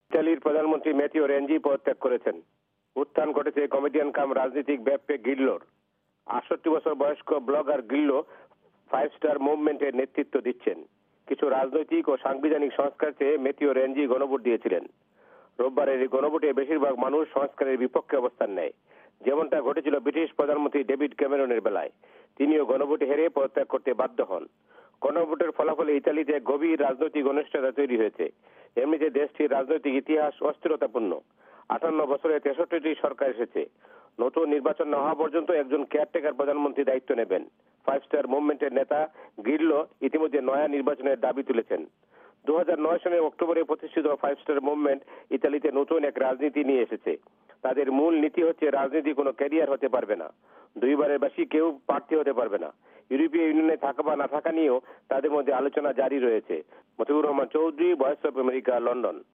লন্ডন থেকে